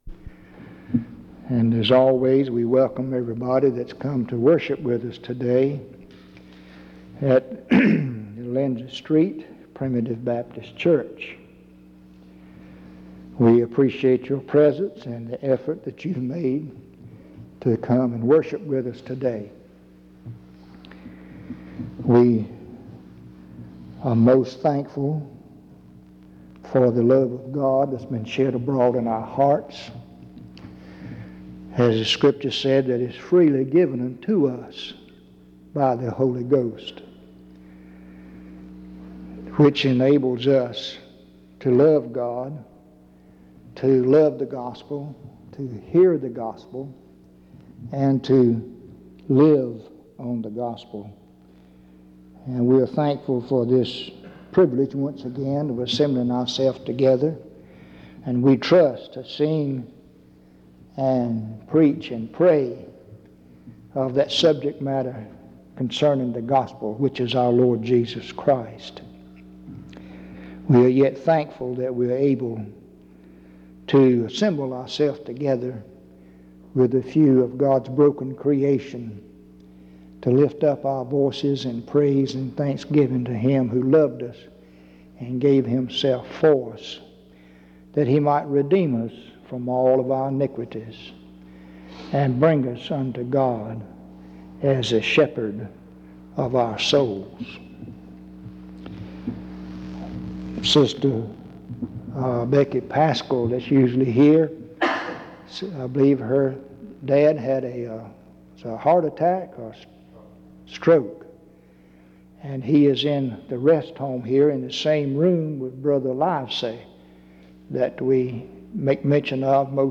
In Collection: Reidsville/Lindsey Street Primitive Baptist Church audio recordings Thumbnail Title Date Uploaded Visibility Actions PBHLA-ACC.001_037-A-01.wav 2026-02-12 Download PBHLA-ACC.001_037-B-01.wav 2026-02-12 Download